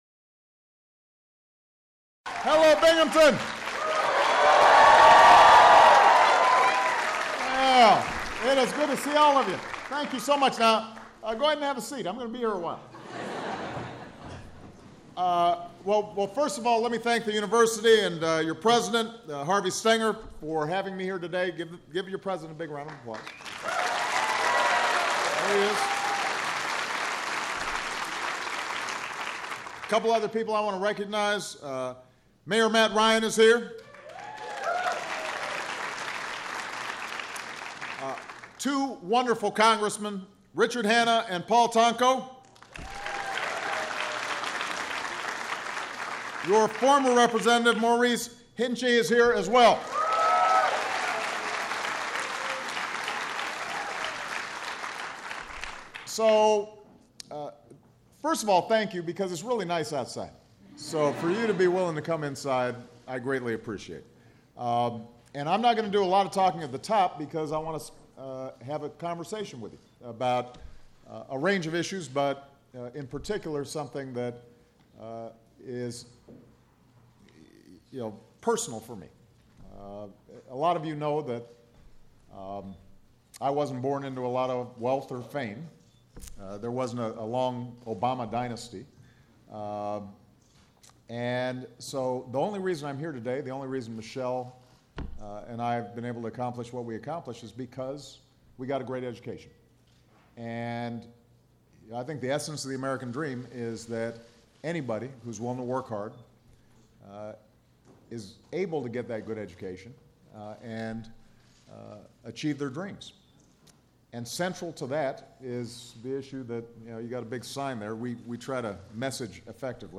U.S. President Barack Obama speaks to students about higher education and outlines his plan to make college more affordable